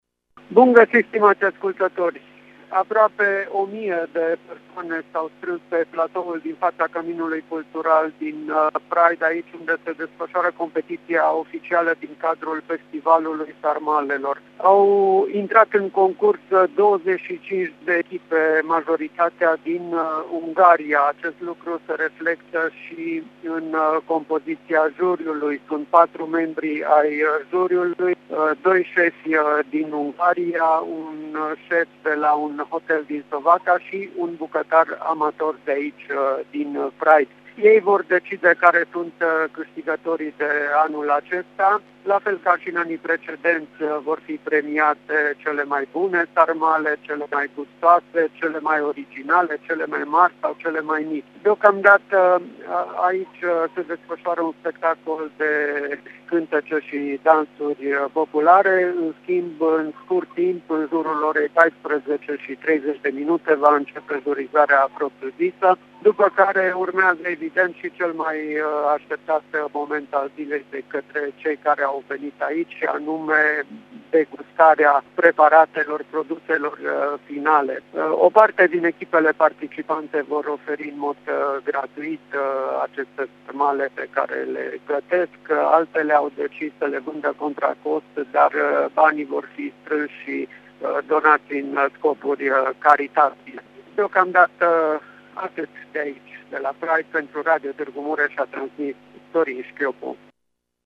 Cea de-a 24-a ediție a Festivalului Sarmalelor a reunit cei mai faimoși bucătari ai zonei, care își etalează talentul în pregătirea faimosului preparat.